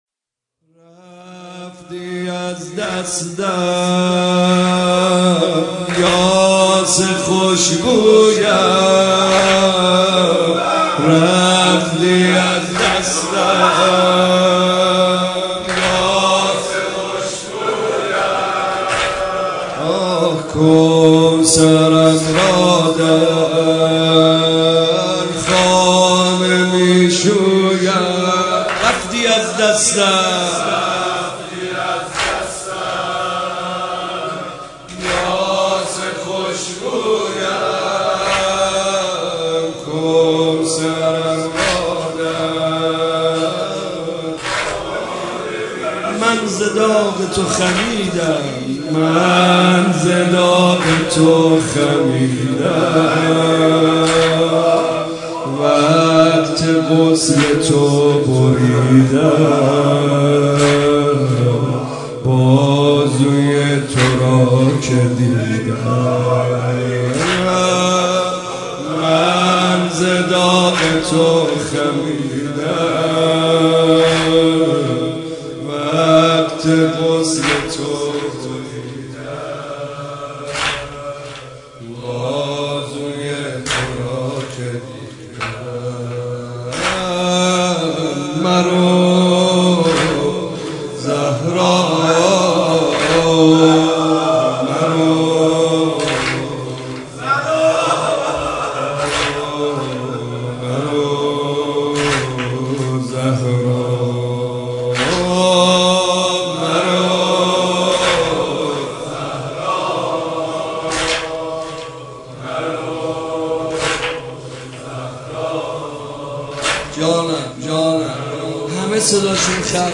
مداحی فاطمیه 1395 «رفتی از دستم یاس خوشبویم
[شب پنجم فاطمیه اول] [سینه زنی واحد]